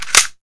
k98_boltback2.wav